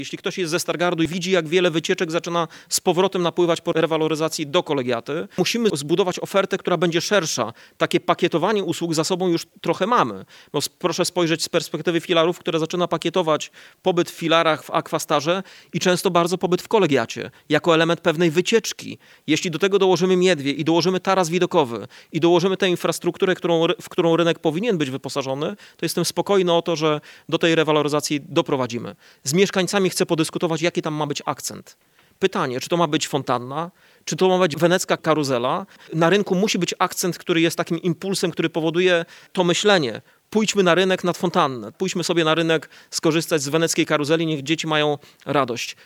W jego wizji Rynek ma stać się nie tylko perełką architektury, ale i magnesem na turystów, oferując im coś więcej niż tylko piękne widoki. Podczas konferencji prasowej ujawnił plany ożywienia tego miejsca i stworzenia kompleksowej oferty, która uczyni Stargard prawdziwą perłą Pomorza Zachodniego.